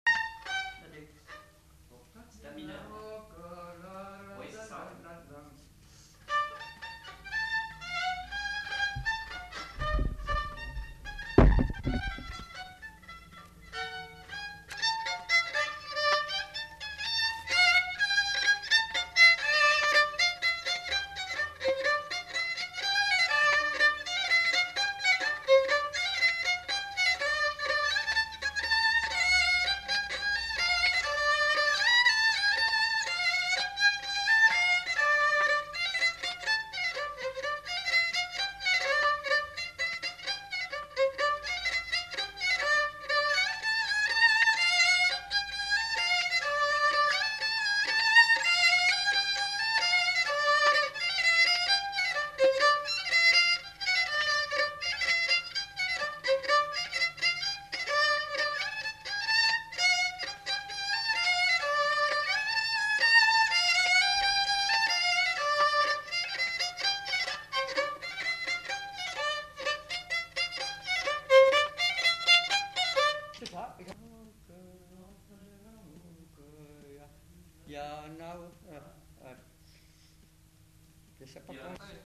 Lieu : Casteljaloux
Genre : morceau instrumental
Instrument de musique : violon
Danse : rondeau